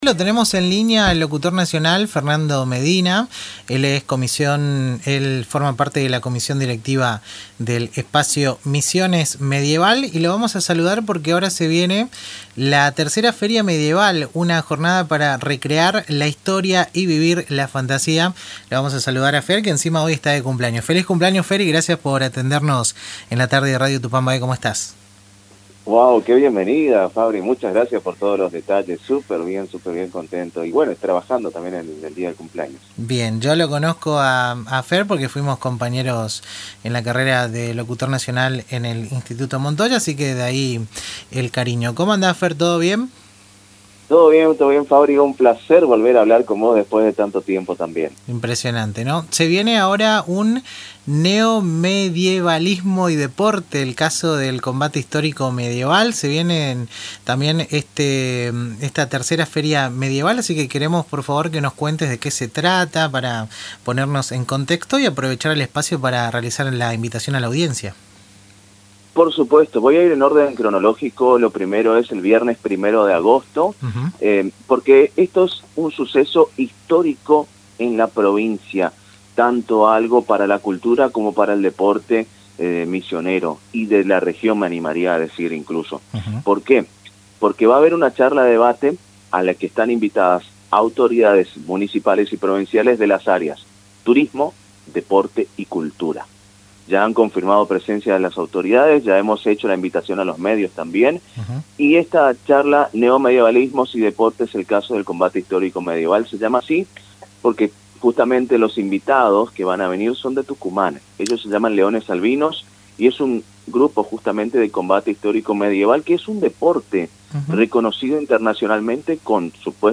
en diálogo con Radio Tupambaé.